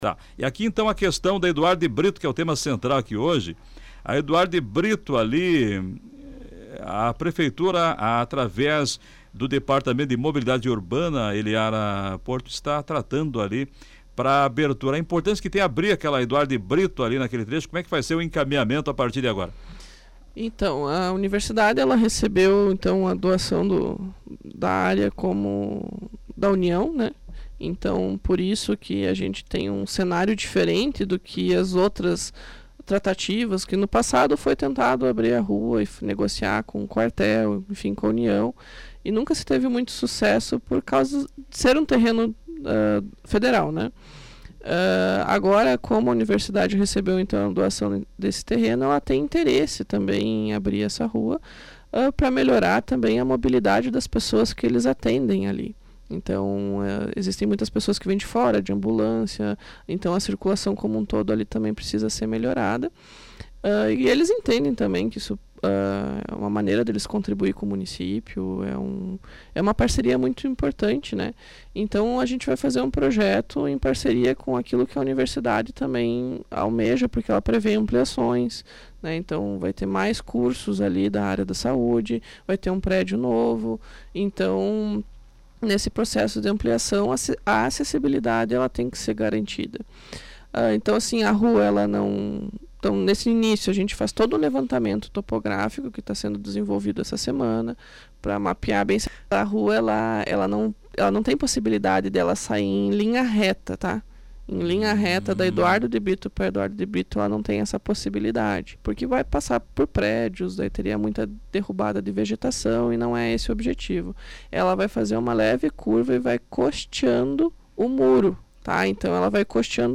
Ela esteve no programa Comando Popular